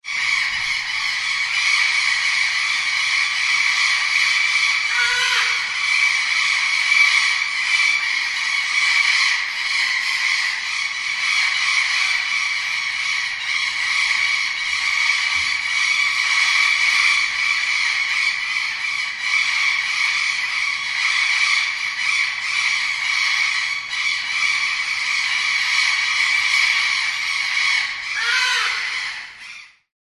На этой странице собраны звуки, которые издают попугаи: от веселого чириканья до мелодичного пения.
Шум стаи попугаев, перекликающихся в тропических джунглях